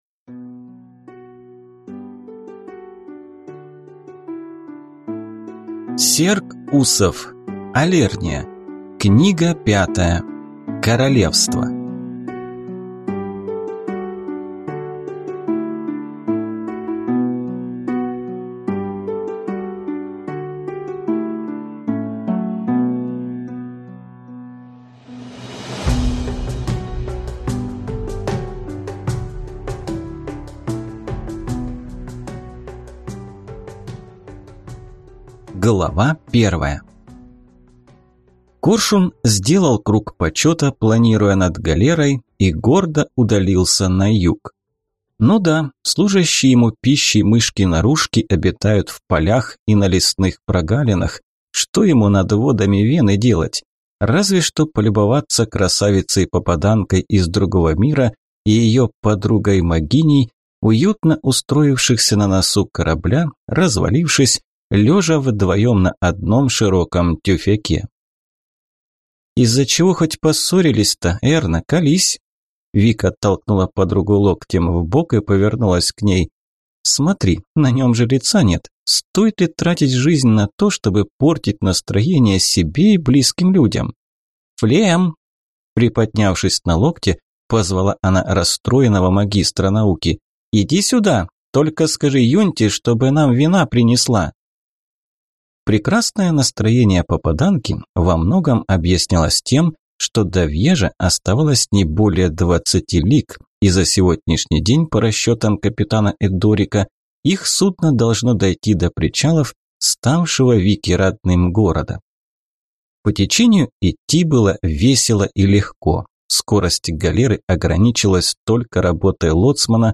Аудиокнига Алерния. Королевства | Библиотека аудиокниг
Прослушать и бесплатно скачать фрагмент аудиокниги